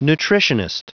Prononciation du mot nutritionist en anglais (fichier audio)
Prononciation du mot : nutritionist